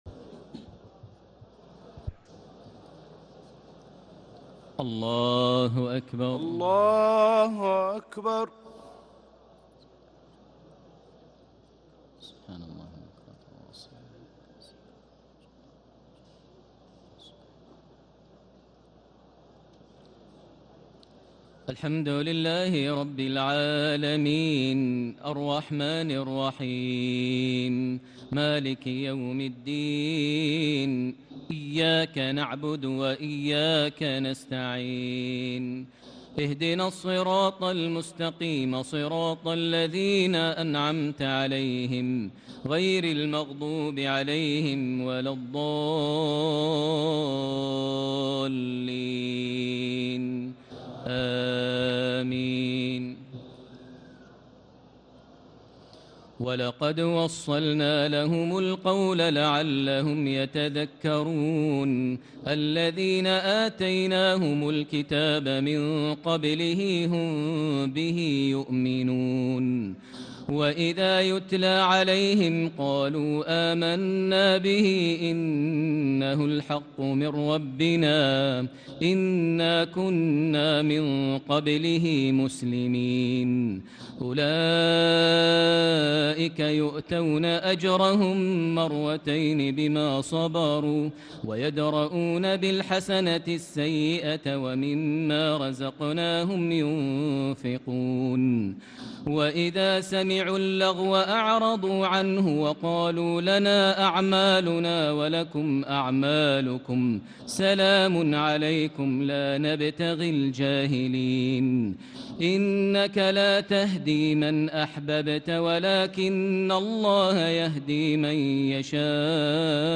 تراويح الليلة التاسعة عشر رمضان 1440هـ من سورتي القصص (51-88) والعنكبوت (1-45) Taraweeh 19 st night Ramadan 1440H from Surah Al-Qasas and Al-Ankaboot > تراويح الحرم المكي عام 1440 🕋 > التراويح - تلاوات الحرمين